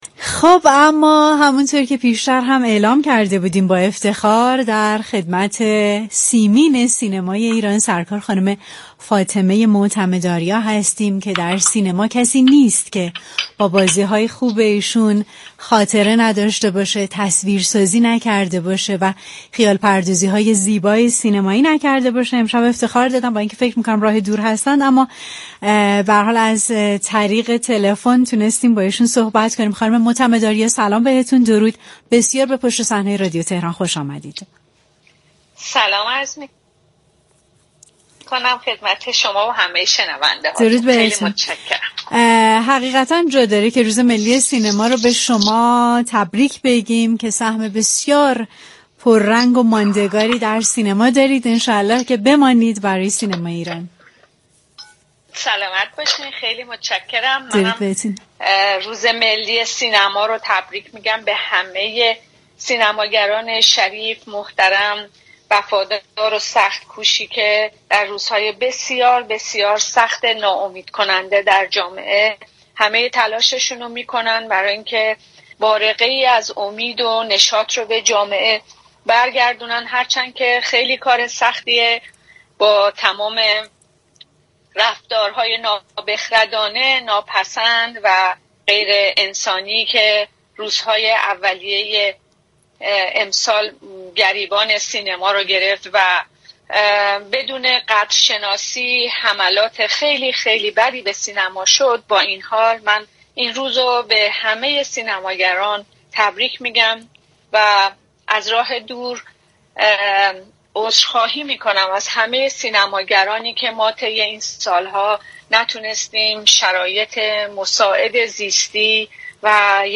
به گزارش پایگاه اطلاع رسانی رادیو تهران، فاطمه معتمد آریا، بازیگر سینما، تلویزیون و تئاتر و سیمین سینمای ایران، به مناسبت روزملی سینما در گفتگو با برنامه پشت صحنه رادیو تهران با تبریك روز ملی سینما گفت: من روز ملی سینما را به همه سینماگران شریف، محترم، وفادار و سخت كوشی كه در روزهای بسیار سخت و ناامیدكننده در جامعه تلاش می كنند تا بارقه‌ای از امید و نشاط را به جامعه برگردانند، تبریك می‌گویم.